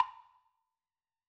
Coffee1.wav